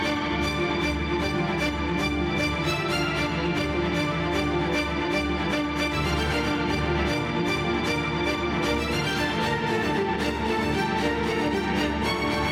弦弓4
标签： 115 bpm Orchestral Loops Strings Loops 2.11 MB wav Key : Unknown Studio One
声道立体声